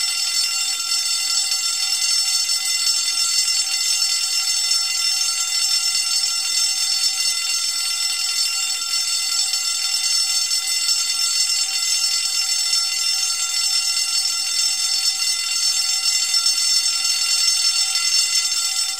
Sons et bruitages d'alarmes gratuits
Alarme sonnette incendie